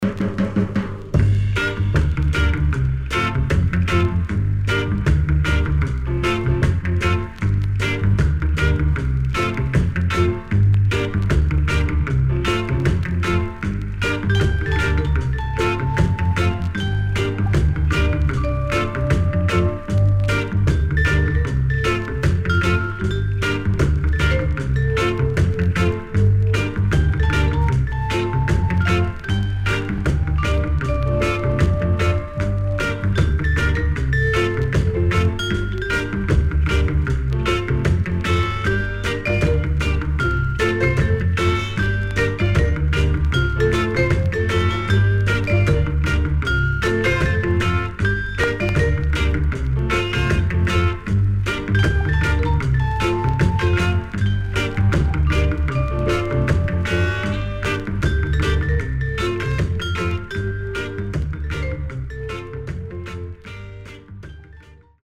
SIDE A:プレス起因により少しチリノイズ、プチノイズ入ります。